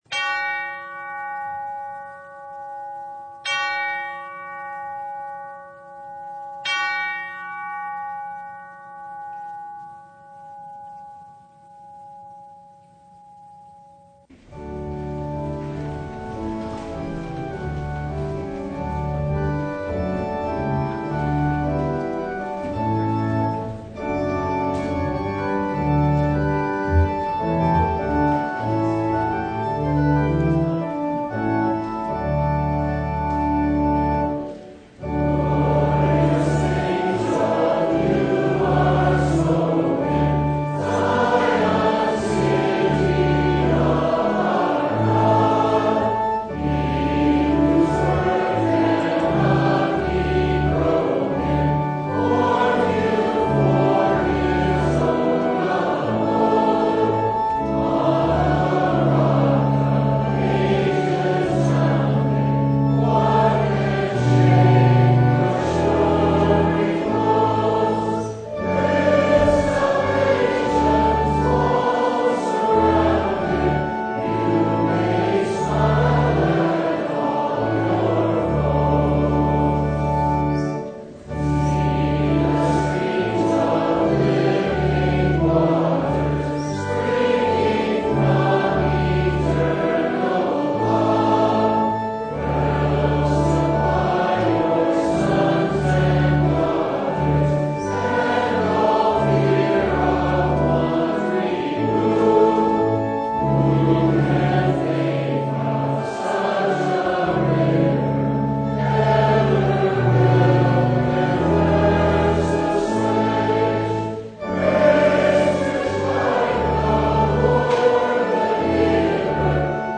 Matthew 14:13-21 Service Type: Sunday Five loaves